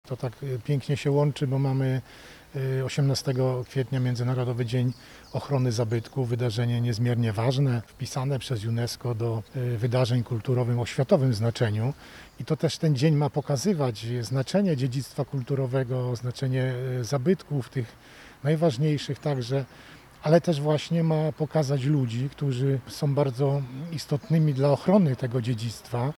Medale Zasłużony Kulturze Gloria Artis w imieniu ministra kultury, dziedzictwa narodowego i sportu wręczył wojewoda lubuski Władysław Dajczak: